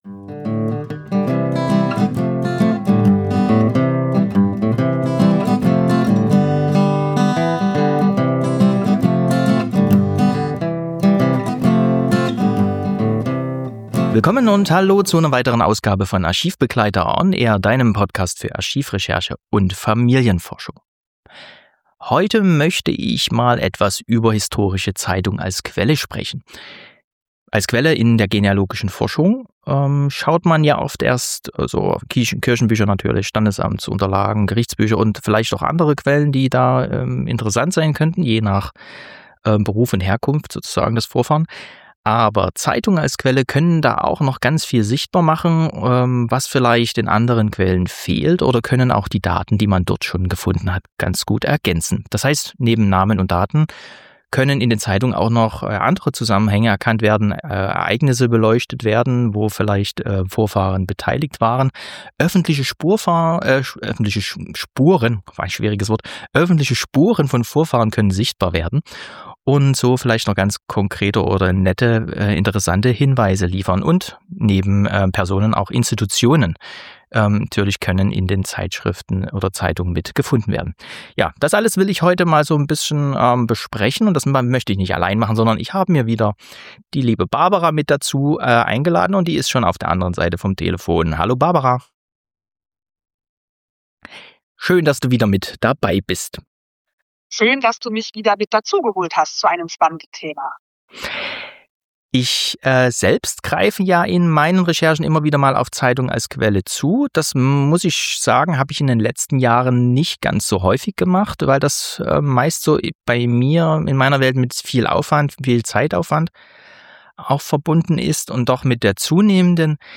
Genealogischer Wochentalk: Historische Zeitungen als Quelle für Familienforschung und Regionalgeschichte ~ ArchivBegleiter ON AIR: Einblicke und Tipps für die Archivrecherche Podcast